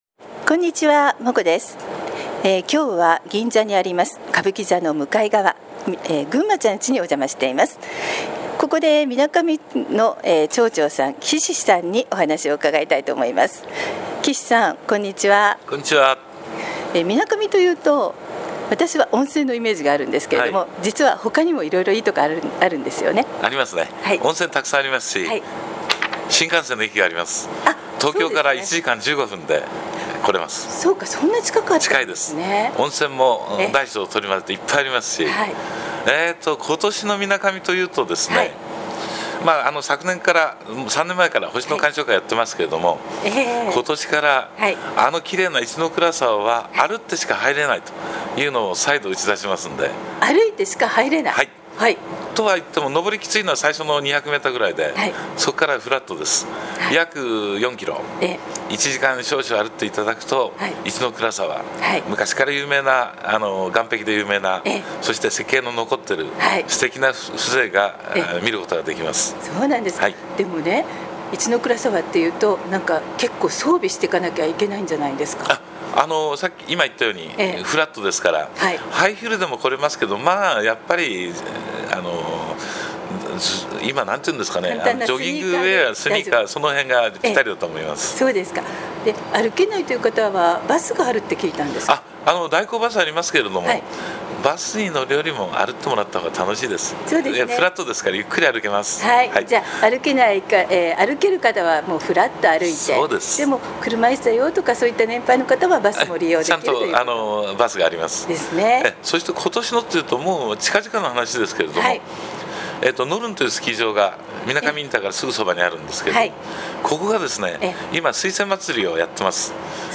みなかみ町　町長　岸良昌さんインタビュー